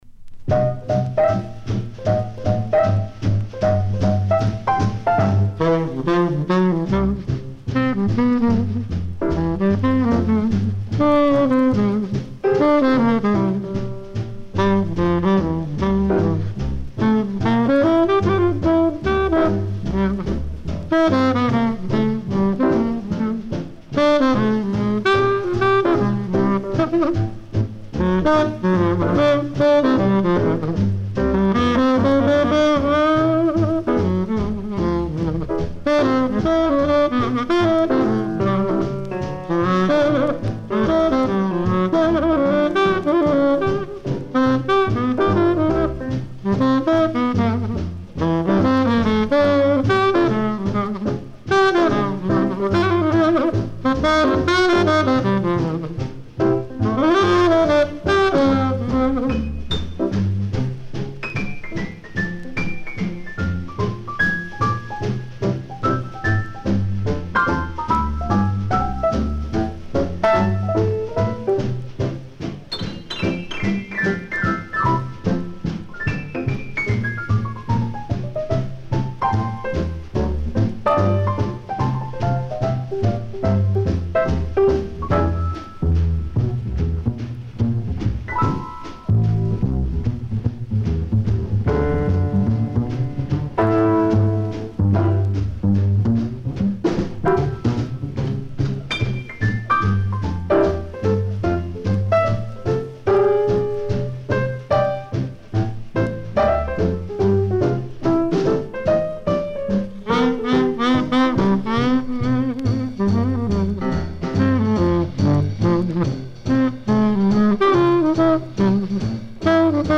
tenor sax